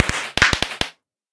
twinkle.ogg